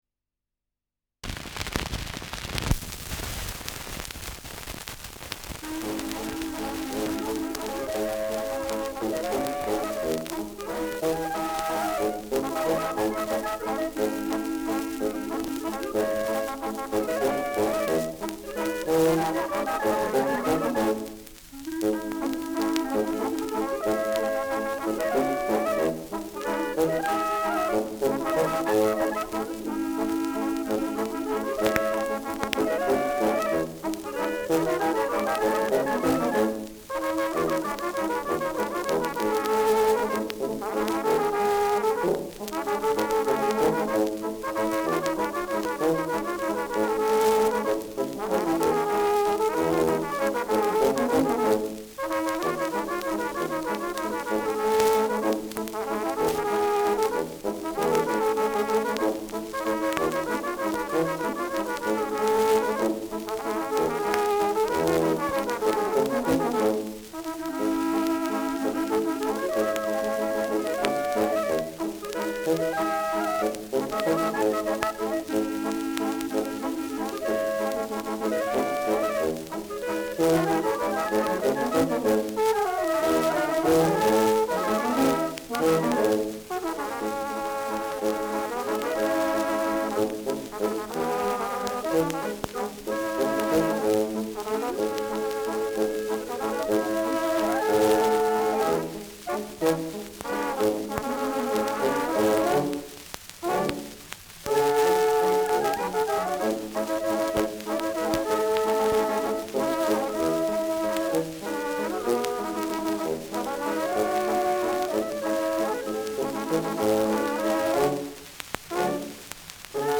Schellackplatte
präsentes Rauschen